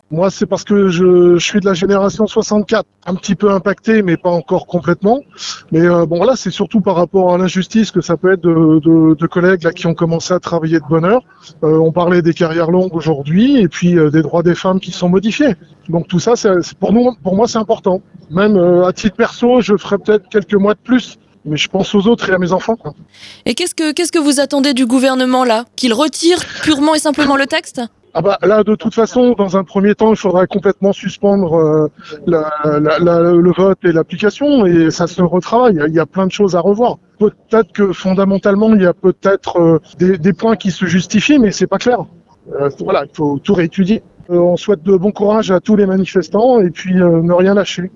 La rédaction de JAIME Radio a recueilli vos témoignages.